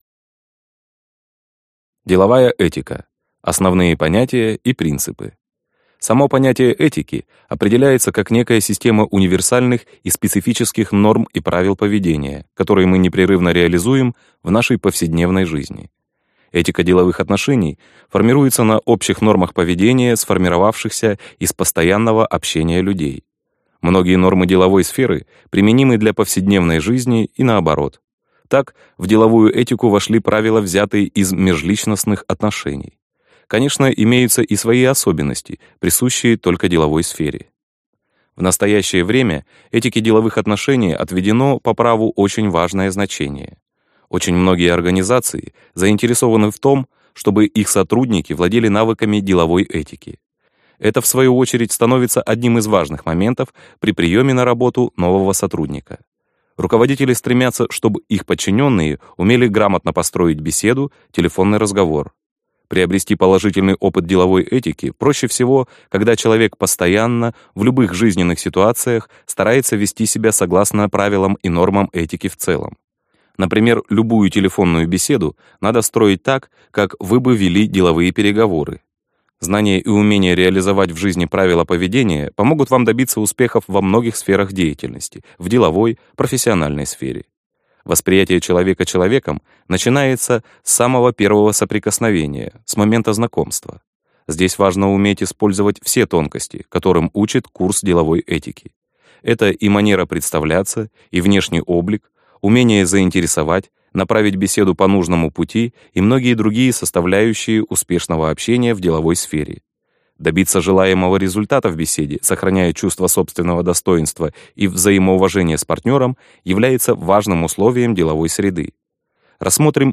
Аудиокнига Лекции по навыкам делового общения | Библиотека аудиокниг
Прослушать и бесплатно скачать фрагмент аудиокниги